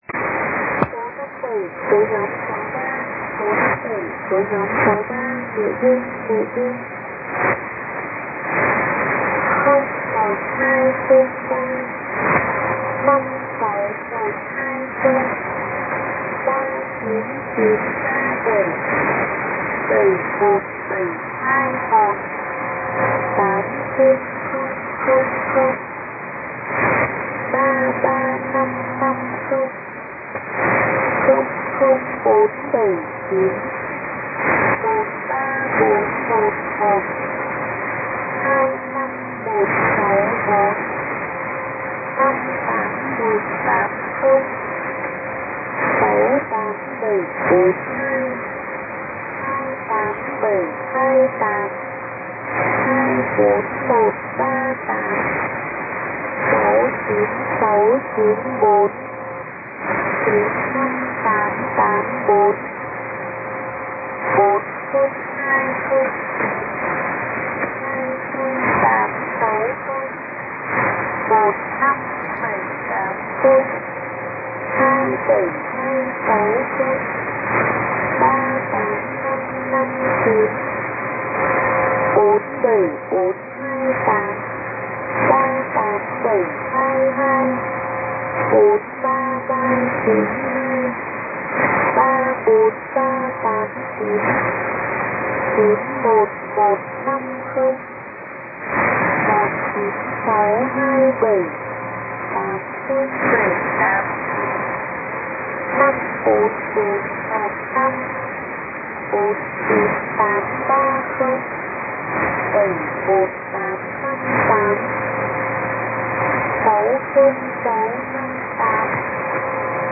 In this example audio from the first message sent on March 11 is in the left channel, while audio from the second message of the day is for March 30 is in the right channel. I have intentionally left them very slightly out of sync so that any differences between the two would be more pronounced. You should also be able to hear different atmospheric noise and fades in each channel.